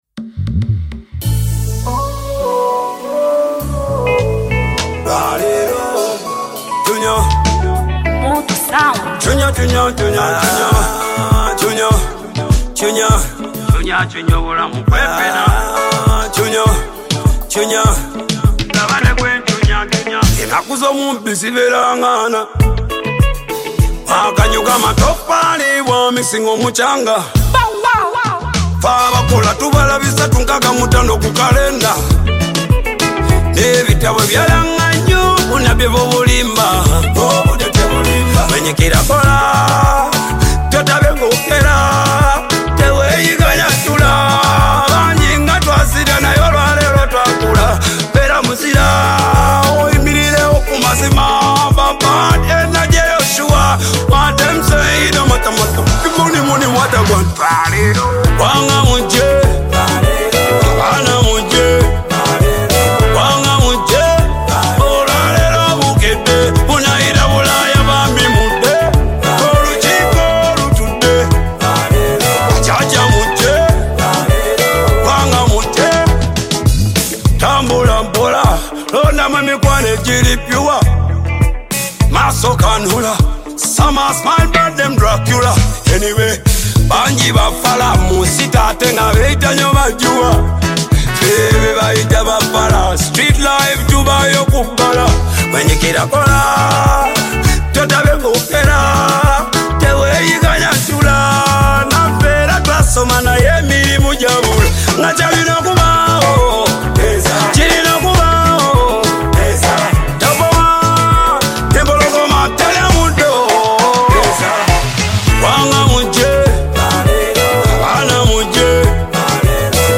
AfroBeat
African Music